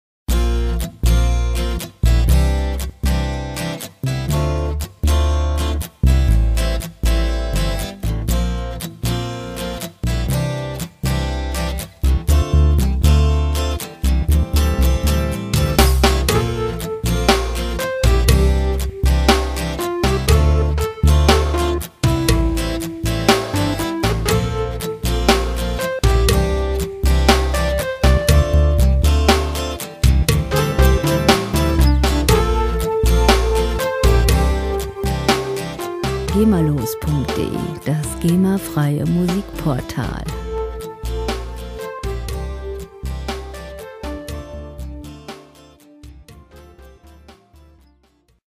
Musikstil: Pop
Tempo: 60 bpm
Tonart: F-Dur
Charakter: natürlich, leger
Instrumentierung: Akustikgitarre, E-Piano, Bass, Drums